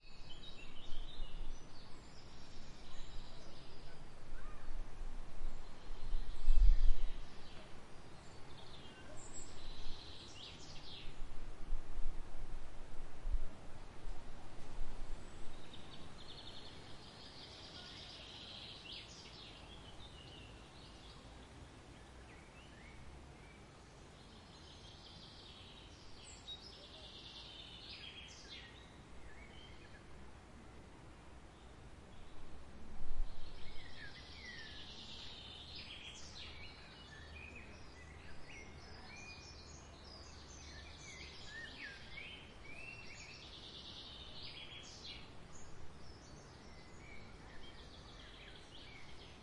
鸟叫声
描述：那是下午晚些时候所以我利用了房子周围所有吵闹的鸟儿。只是各种鸟类的基本嘎嘎声。
标签： 下午 调用 森林 通话 环境 早晨 公园 自然 现场记录
声道立体声